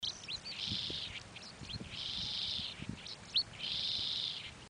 Cachirla Común (Anthus correndera)
Fase de la vida: Adulto
Localidad o área protegida: Reserva Natural del Pilar
Condición: Silvestre
Certeza: Fotografiada, Vocalización Grabada